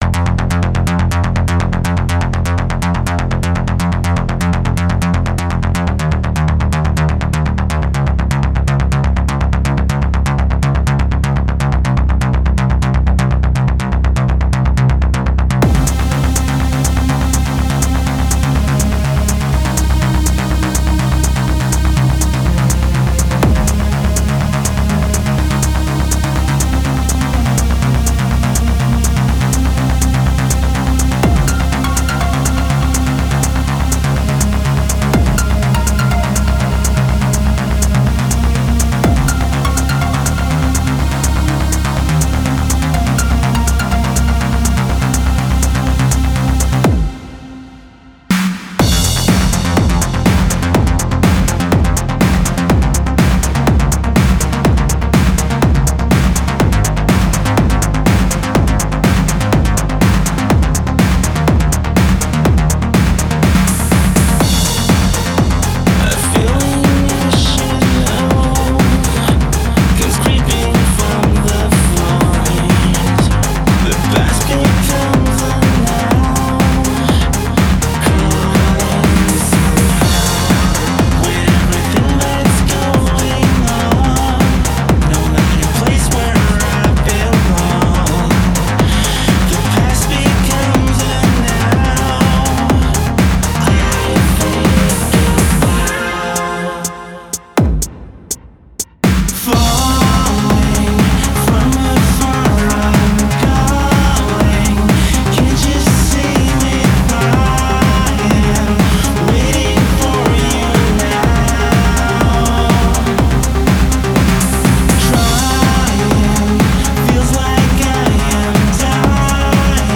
EBM and Industrial net radio show